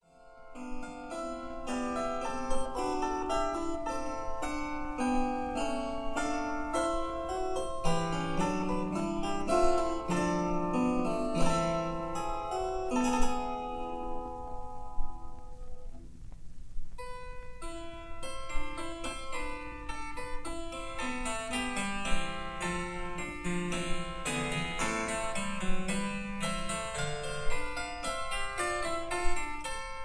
harpsichord